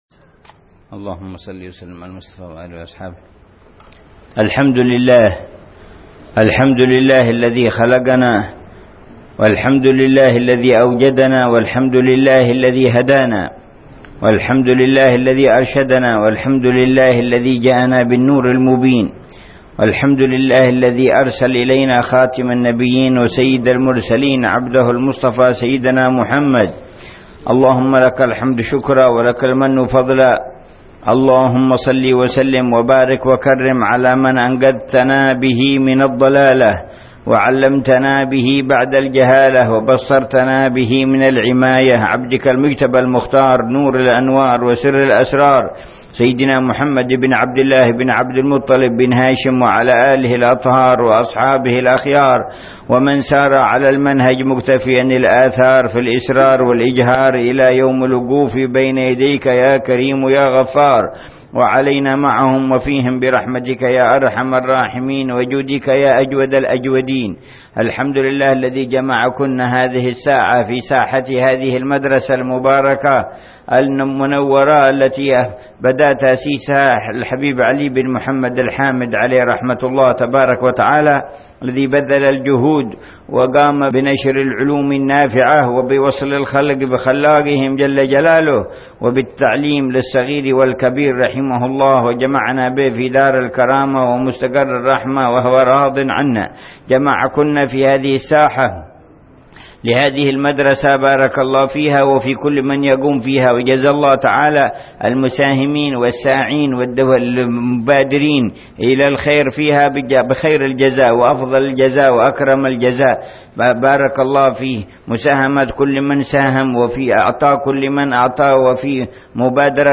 محاضرة للنساء في مدرسة الحبيب علي بن محمد الحامد في سيحوت عصر الثلاثاء 14 ربيع ثاني 1431هـ.